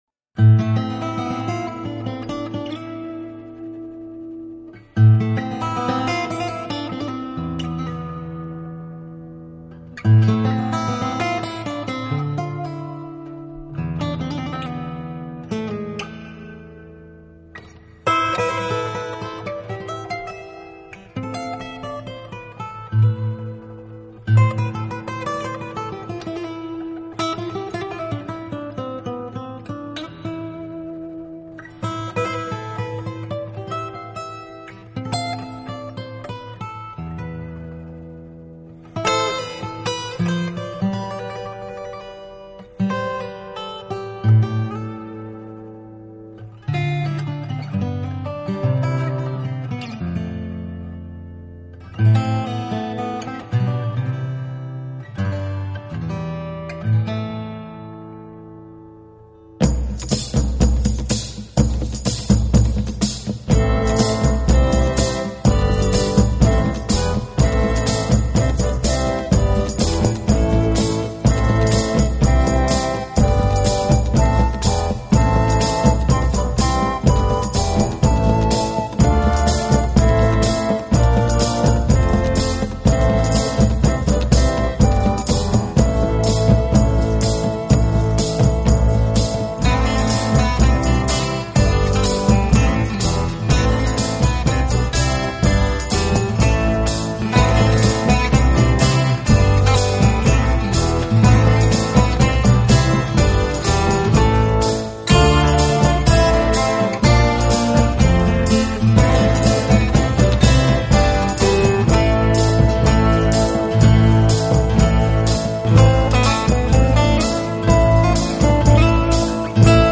Genre: Folk Rock/Neo-Medieval